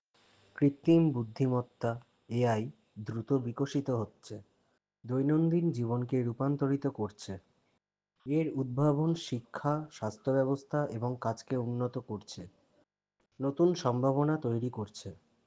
speech
emotional-speech
natural-speech